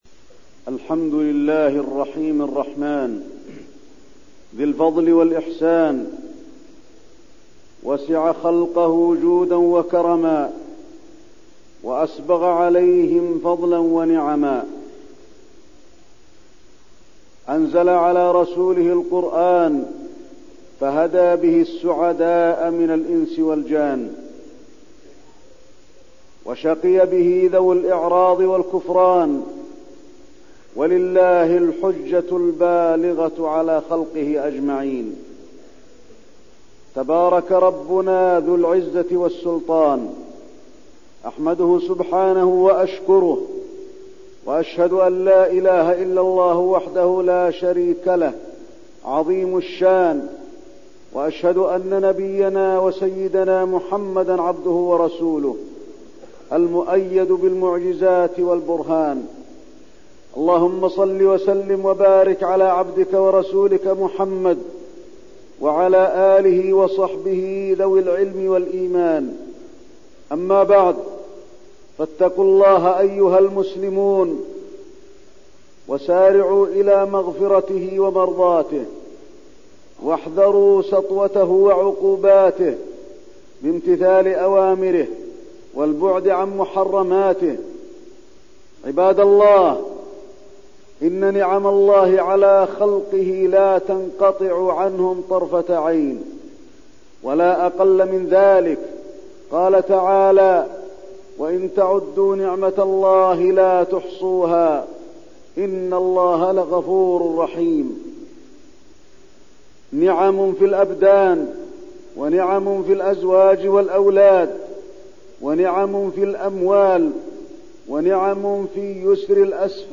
تاريخ النشر ١٦ رمضان ١٤١٢ هـ المكان: المسجد النبوي الشيخ: فضيلة الشيخ د. علي بن عبدالرحمن الحذيفي فضيلة الشيخ د. علي بن عبدالرحمن الحذيفي اغتنام العشر الاواخر من رمضان